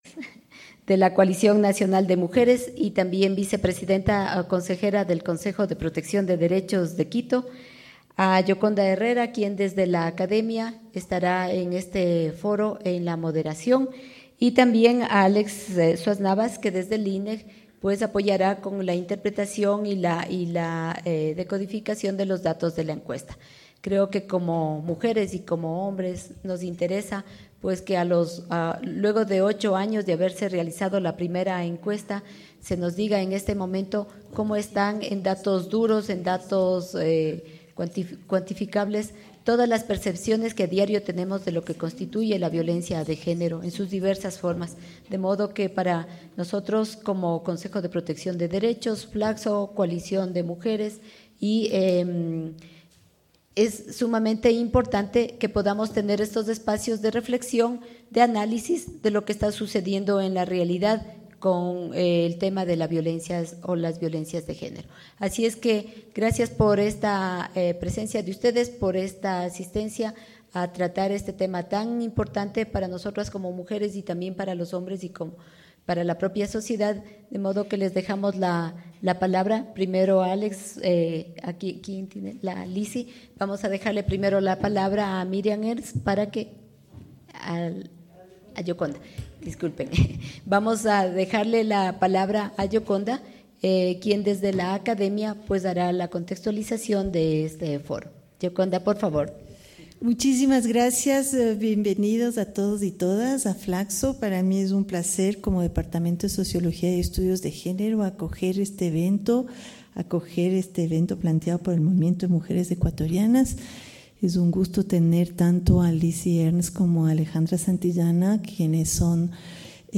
Foro II Encuesta de violencia de género en Ecuador: realidades y desafíos.